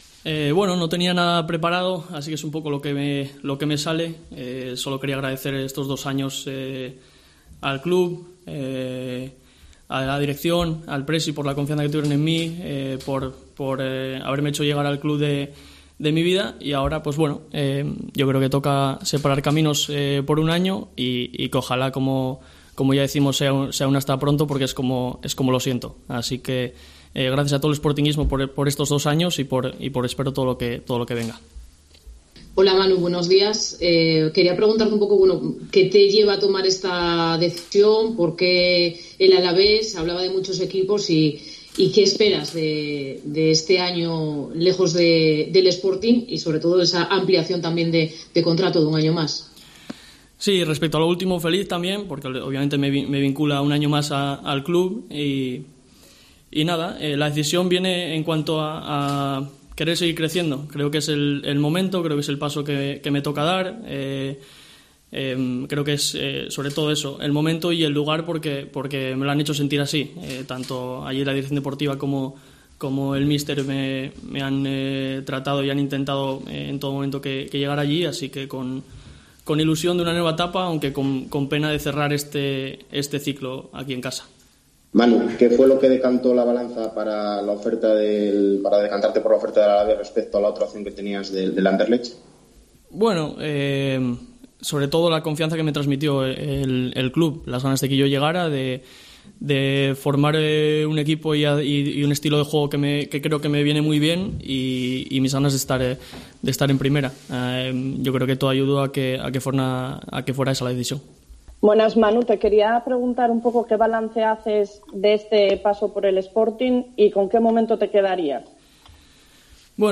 Rueda de prensa de despedida de Manu García